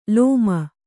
♪ lōma